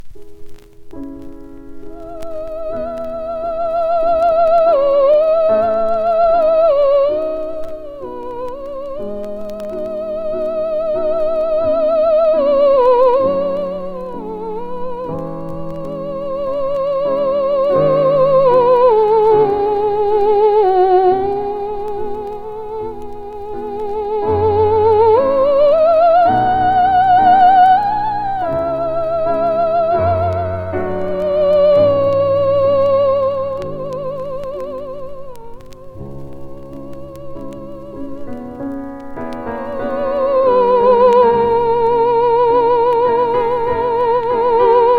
超絶な演奏に驚きます。